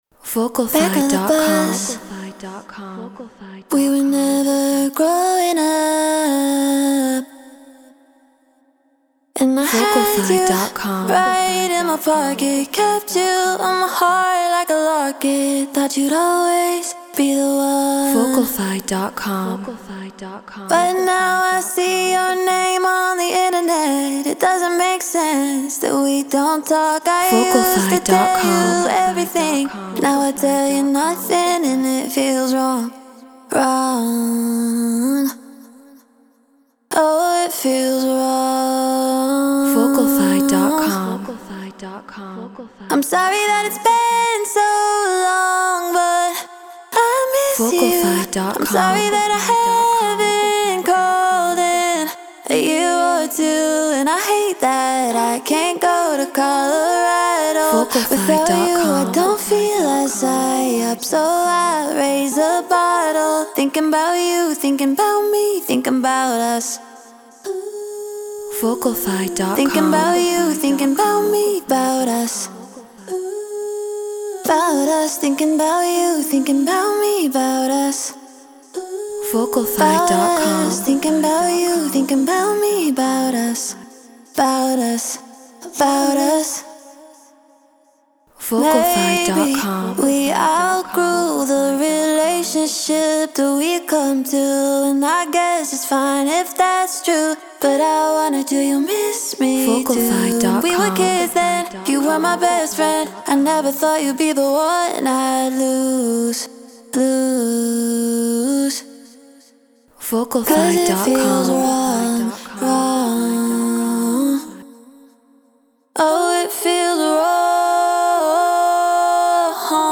Drum & Bass 170 BPM C#maj
Human-Made
Shure SM7B Scarlett 2i2 4th Gen Ableton Live Treated Room